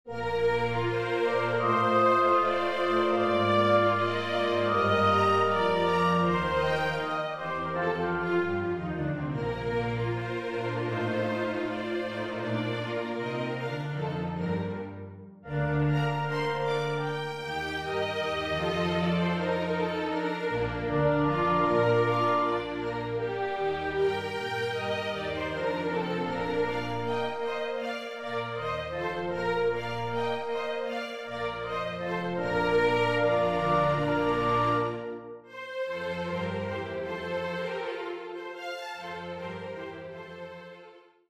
Singspiel
Duett
Kammerorchester-Sound